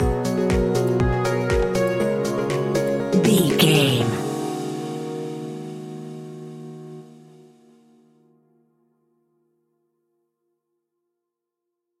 Aeolian/Minor
E♭
groovy
uplifting
hypnotic
dreamy
smooth
piano
drum machine
synthesiser
electro house
funky house
instrumentals
synth leads
synth bass